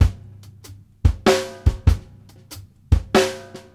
HAT       -L.wav